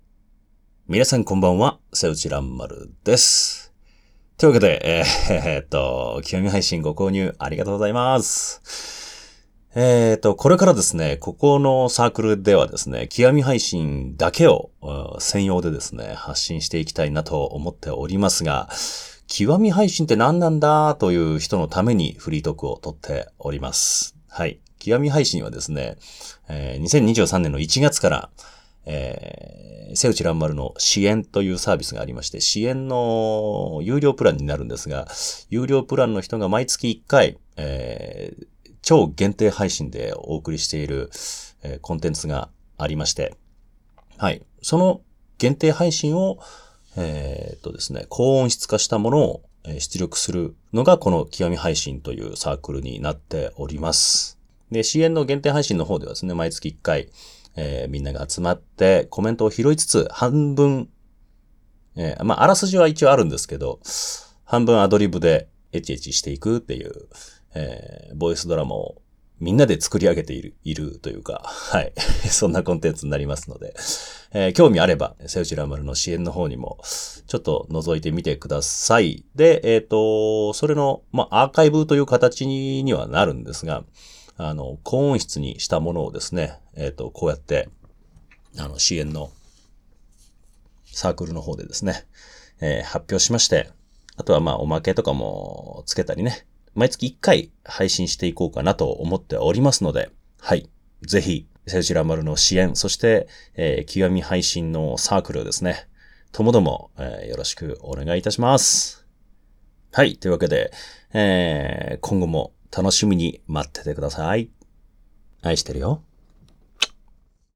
ASMR
フリートーク.mp3